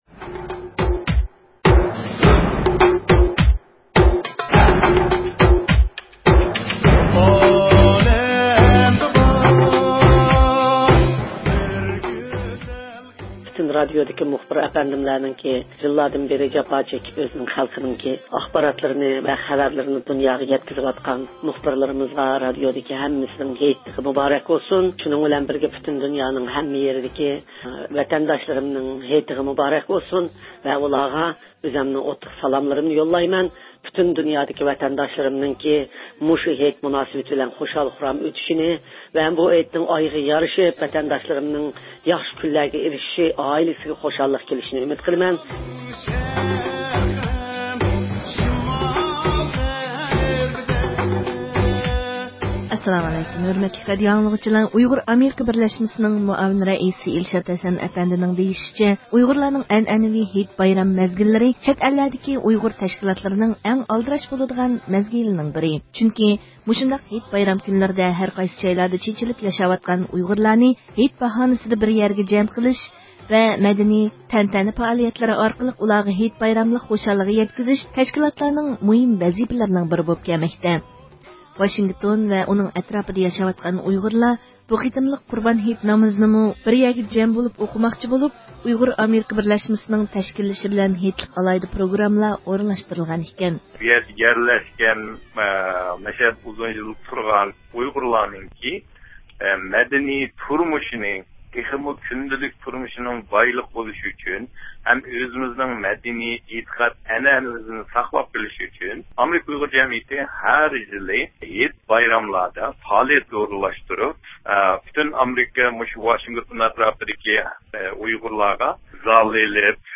يۇقىرىدىكى ئاۋاز ئۇلىنىشىدىن، ئۇيغۇرلارنىڭ مەنىۋى ئانىسى رابىيە قادىر خانىمنىڭ ھېيتلىق ئامانىتىنى ۋە مۇخبىرىمىزنىڭ بىر قىسىم ئۇيغۇرلار بىلەن ئۆتكۈزگەن سۆھبىتىنى ئاڭلىغايسىلەر.